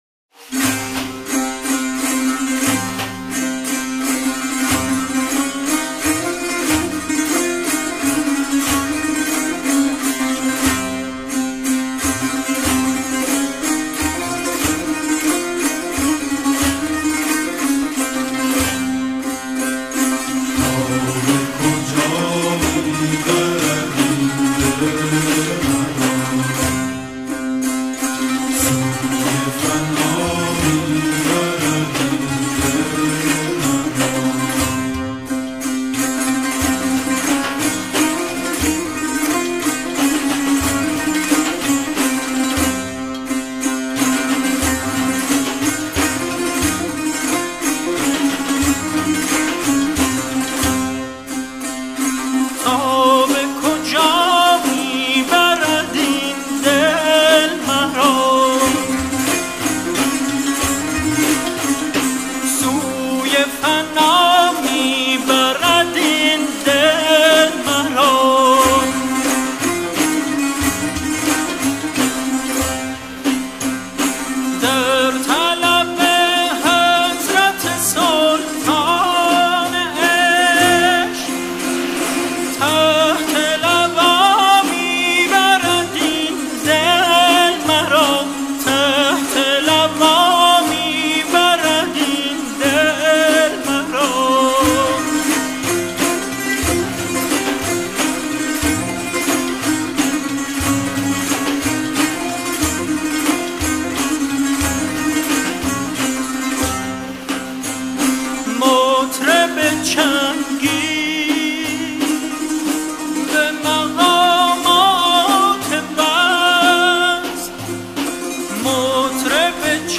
ژانر: سنتی